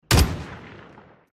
Pack de Efeitos e Transições / Elementos VFX / 08 - Weapon SFX / LMG_s Outros MP3 M91.mp3 cloud_download MP3 MG34.mp3 cloud_download MP3 PKM.mp3 cloud_download MP3 SA87 SHOTGUN.mp3 cloud_download